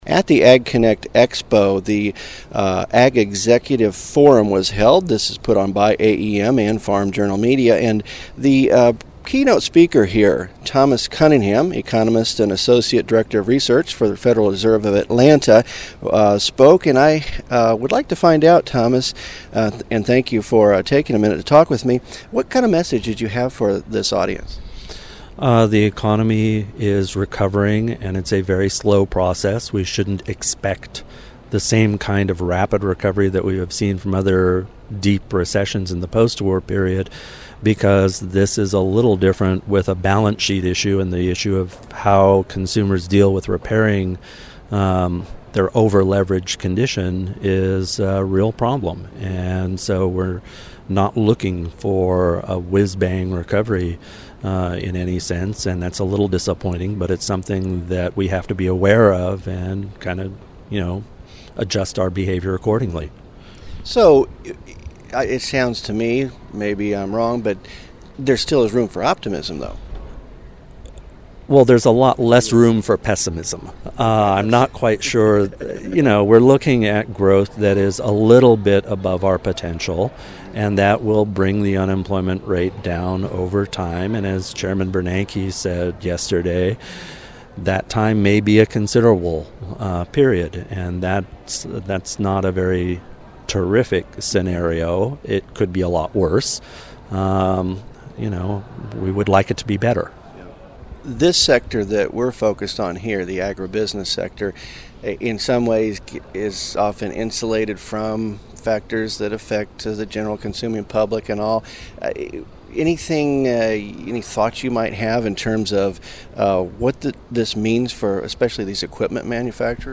AG CONNECT ExpoThis morning at AG CONNECT Expo the first session was the Ag Executive Forum.
Interview